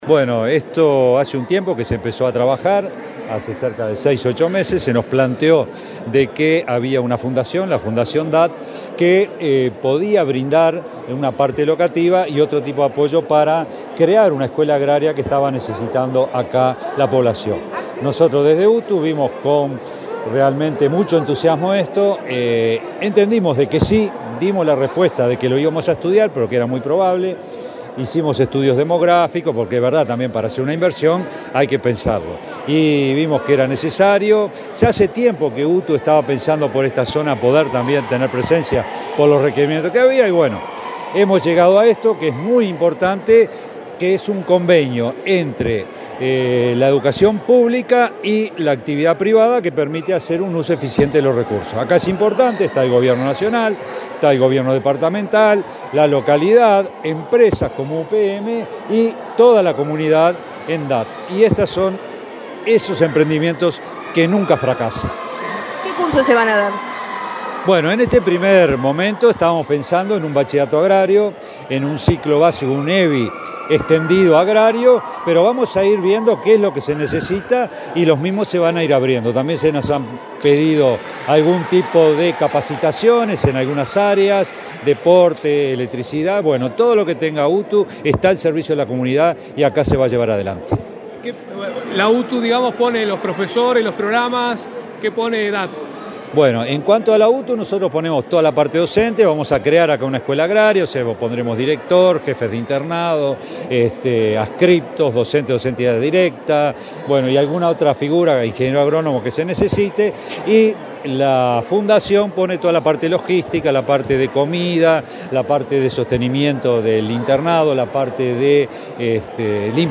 Declaraciones del director general de UTU
Declaraciones del director general de UTU 29/06/2023 Compartir Facebook X Copiar enlace WhatsApp LinkedIn La UTU firmó un convenio con la Fundación DAT Carlos Reyles, de Durazno, que permitirá que ambas puedan llevar adelante programas, proyectos, actividades y cursos sobre temas de interés. Luego el director general de la UTU, Juan Pereyra, dialogó con la prensa.